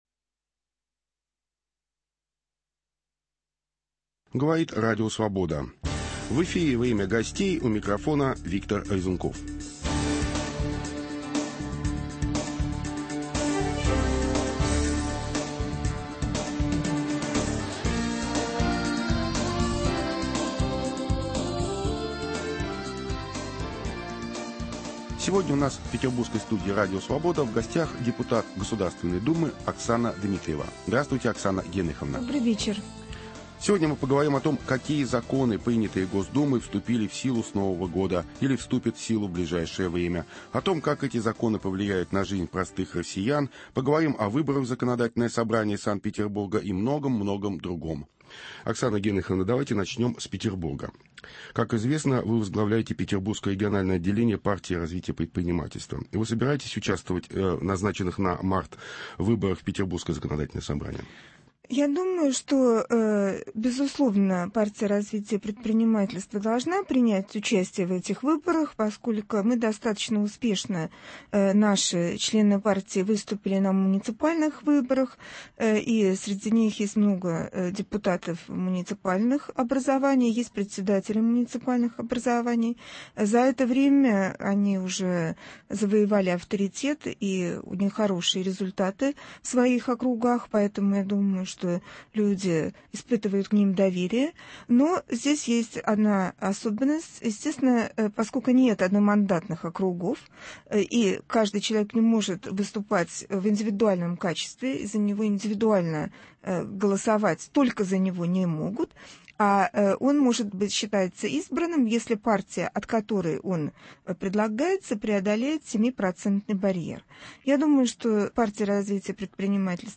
В чем заключается коррупционность и порочность системы распределения льготных лекарств? Об этом и многом другом - в беседе с депутатом Государственной Думы Оксаной Дмитриевой.